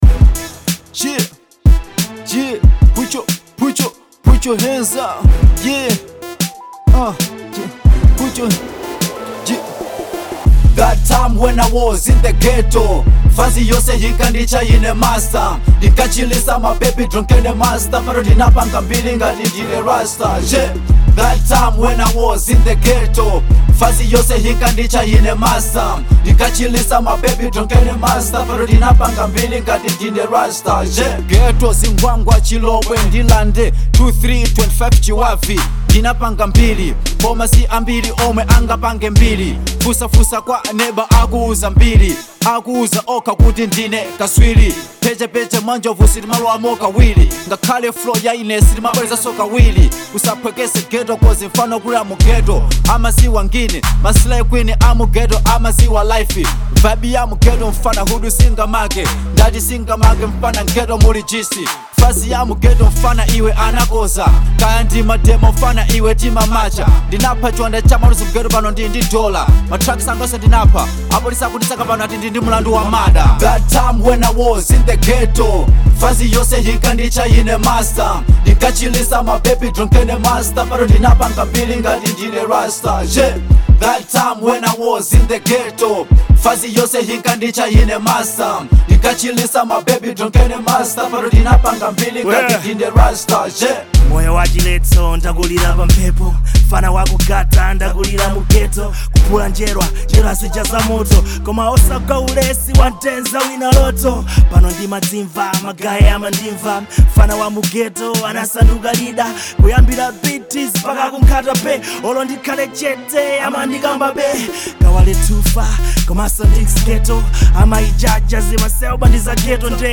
Genre : Hip Hop